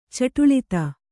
♪ caṭuḷita